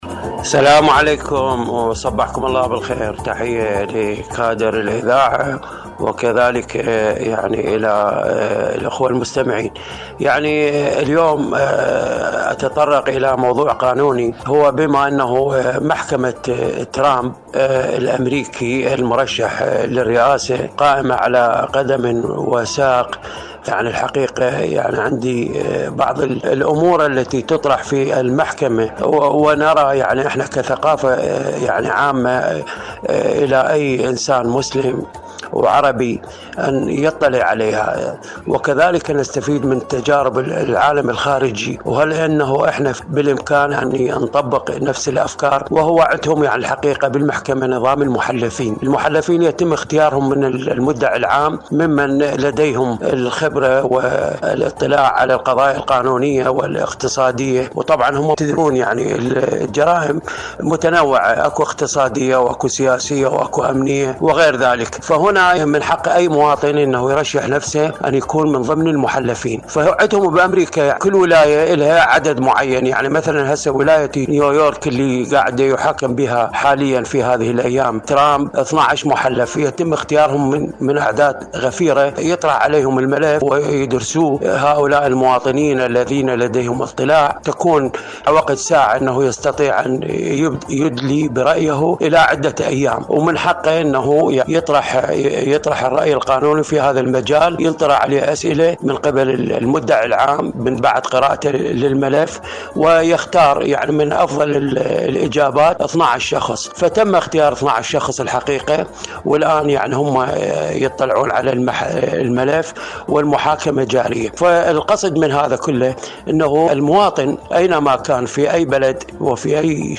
إذاعة طهران- المنتدى الإذاعي